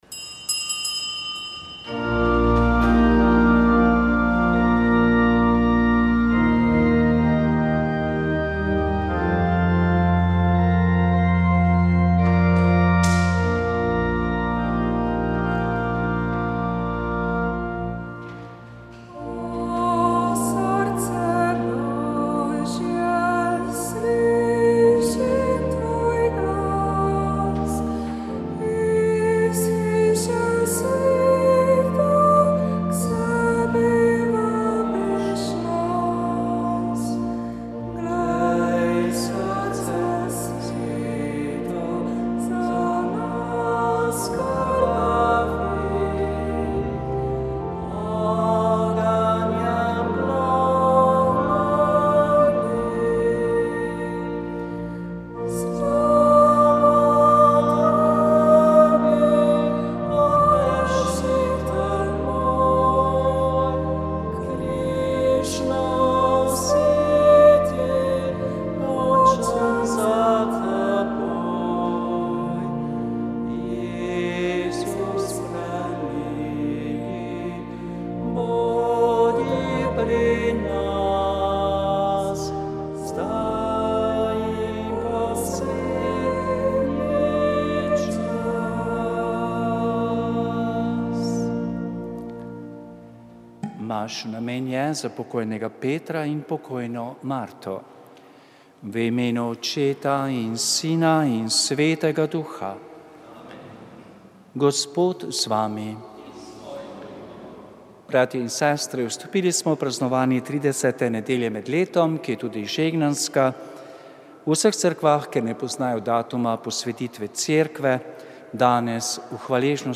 Sveta maša
Prenos svete maše iz bazilike Marije Pomagaj na Brezjah dne 7. 5.